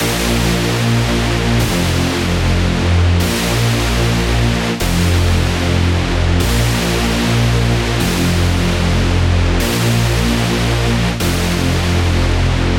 描述：帅气的
标签： 150 bpm Dubstep Loops Bass Synth Loops 2.15 MB wav Key : Unknown
声道立体声